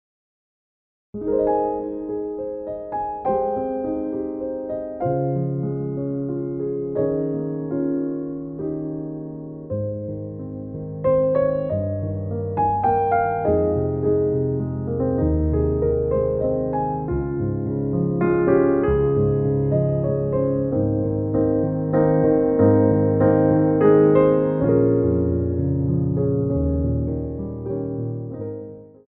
U.K. Artist / Ballet Accompanist
Piano Arrangements of Popular Music
3/4 (16x8)